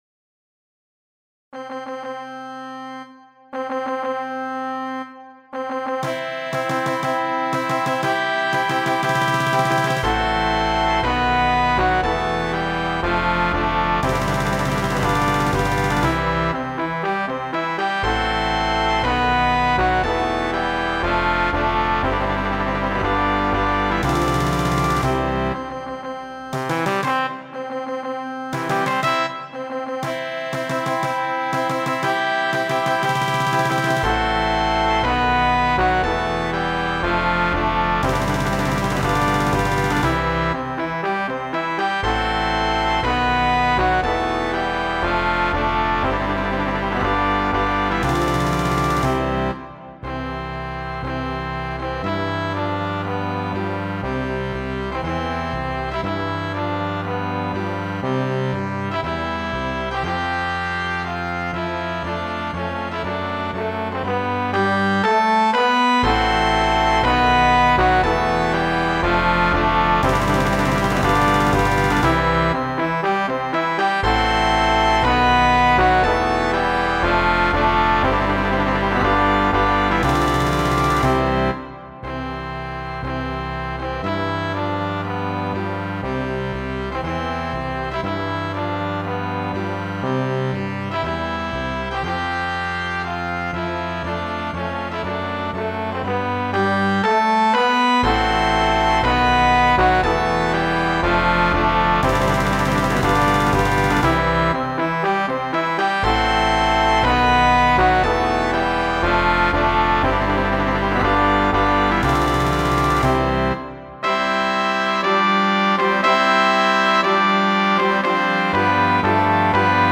2. Brass Band
Full Band
without solo instrument
Classical
Music Sample